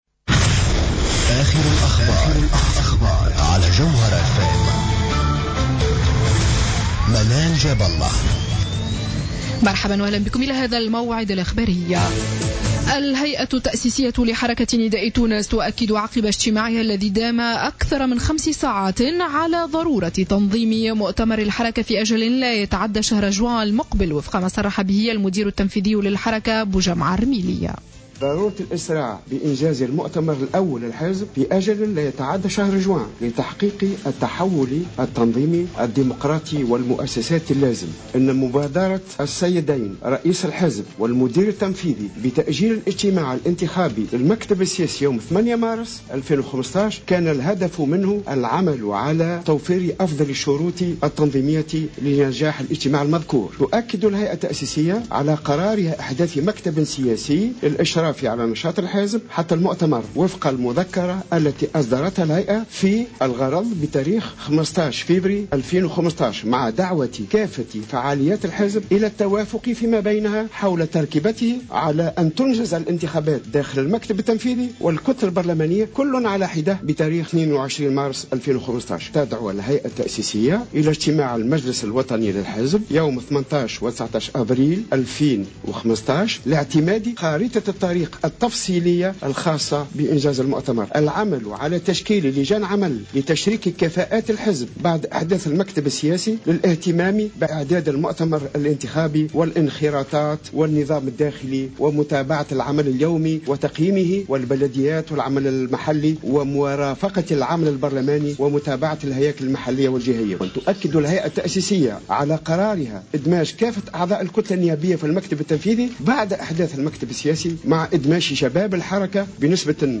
نشرة أخبار منتصف الليل ليوم الاربعاء 11 مارس 2015